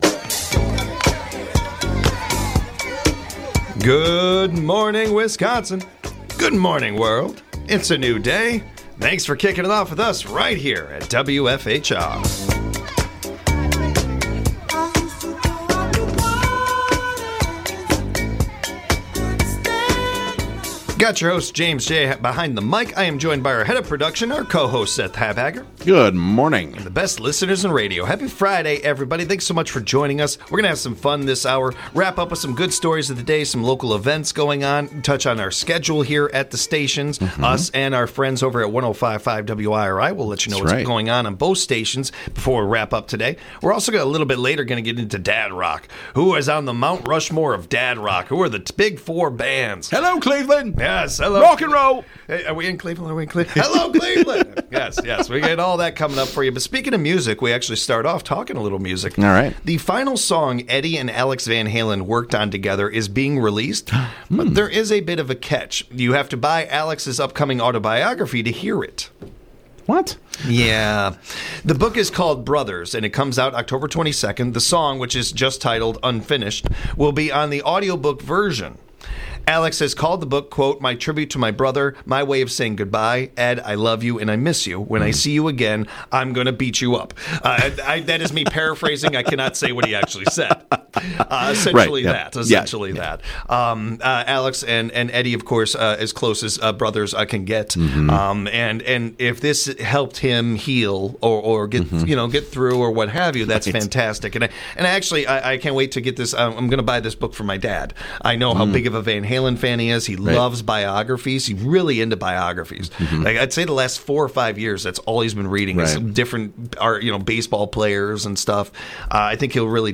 along with a rotation of entertaining co-hosts